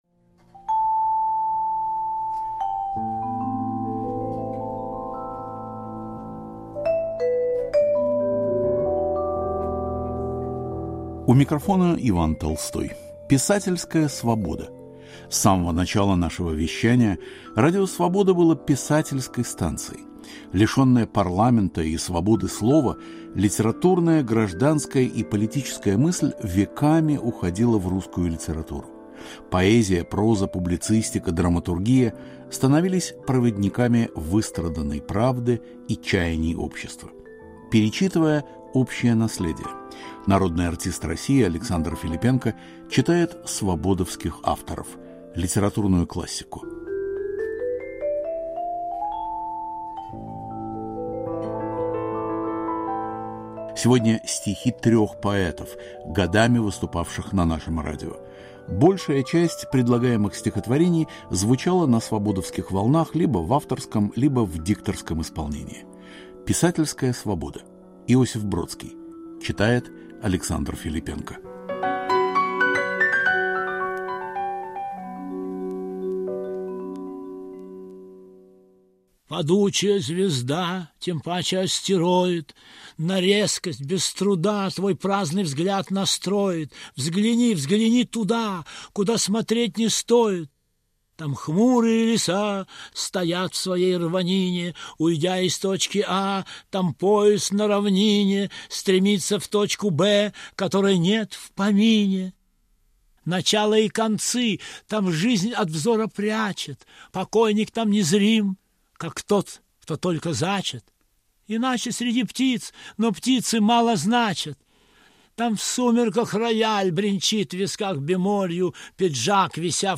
Александр Филиппенко читает стихи Иосифа Бродского, Алексея Цветкова и Льва Лосева
Народный артист России Александр Филиппенко читает свободовских авторов - литературную классику. Сегодня стихи трех поэтов, годами выступавших на нашем радио – Иосифа Бродского, Алексея Цветкова и Льва Лосева.